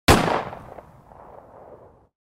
gun shoot.wav